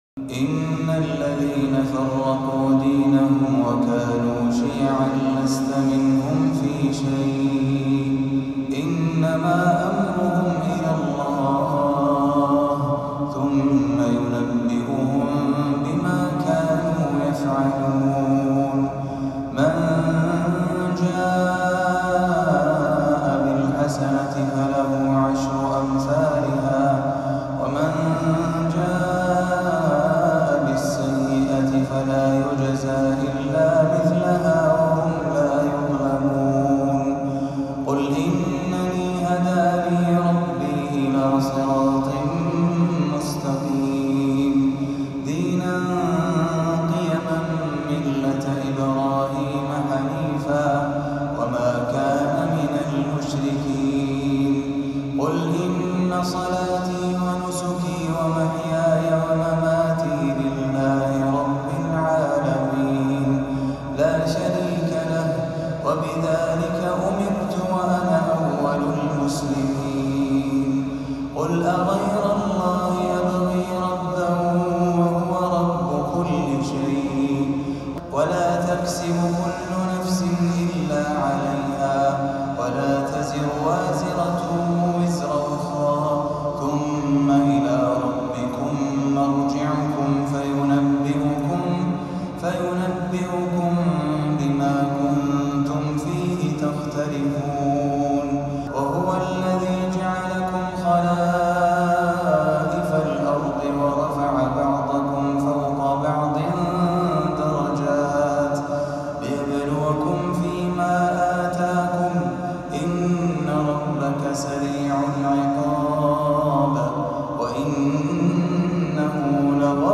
تلاوة متقنة وصوت عذب من سورة الأنعام للقارئ